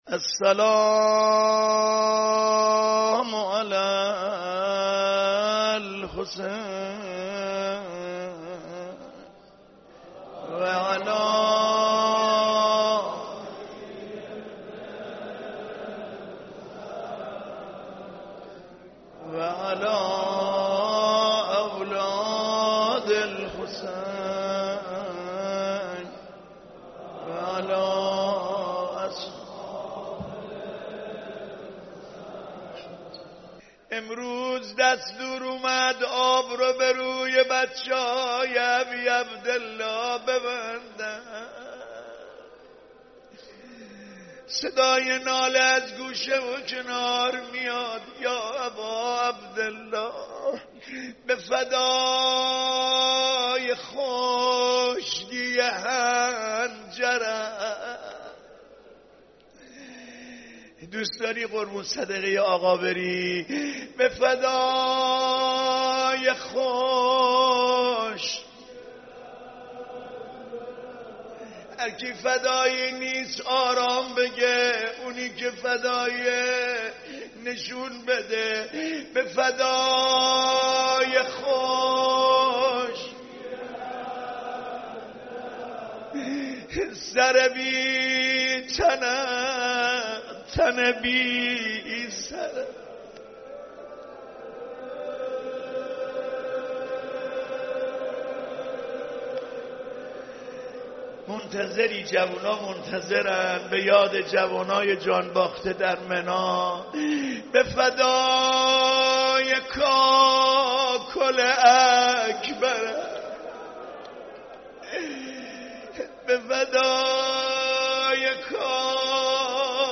صوت/مداحی حاج علی انسانی در محضر رهبر انقلاب